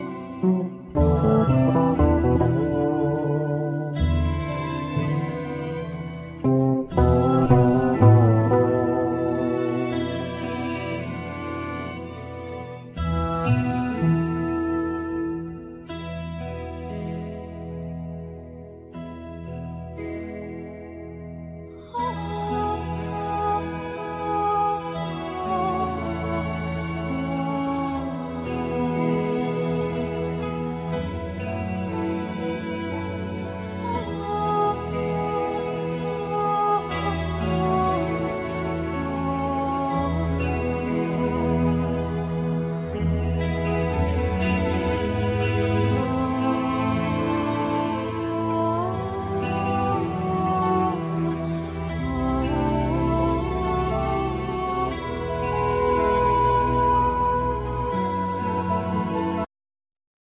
16 string acoustic guitar,El.guitar,Vocals
5 string electric bass
Soprano Saxophone
Piano
1st Violin